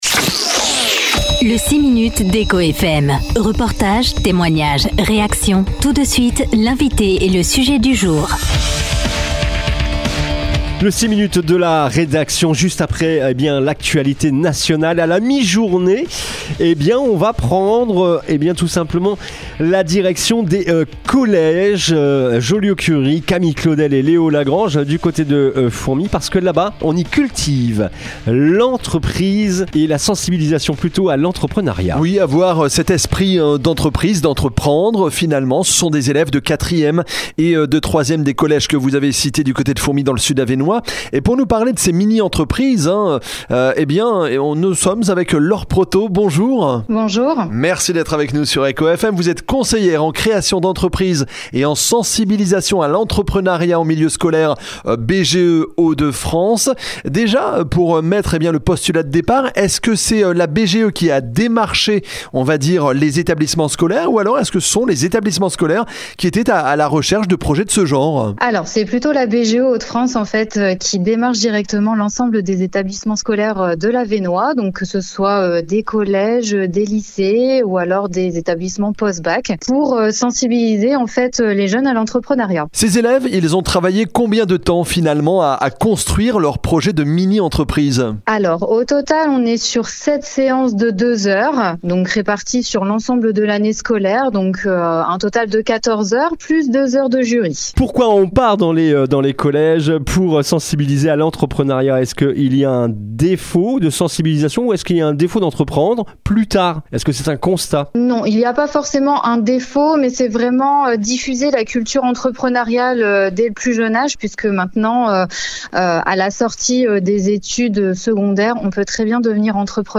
Le 6 minutes ECHO FM